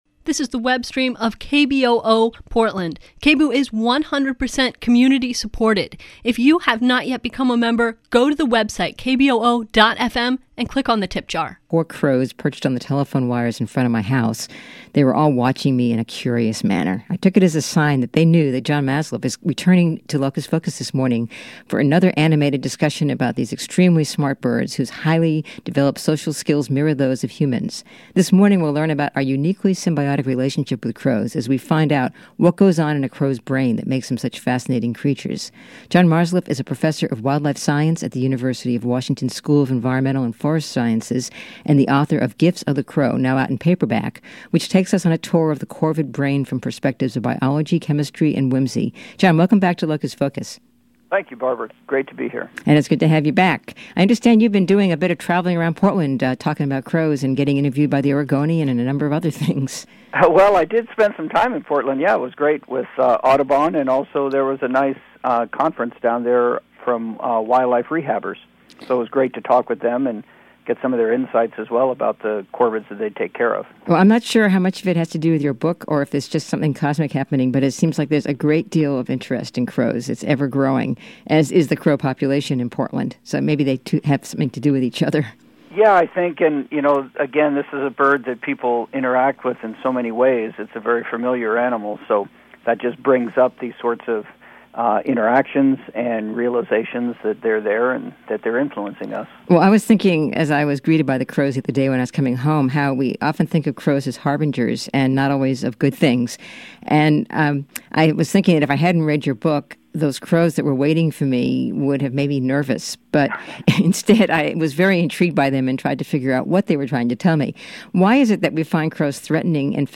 What we have in common with crows, an interview